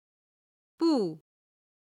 パーは「布(bù)布」